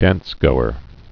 (dănsgōər)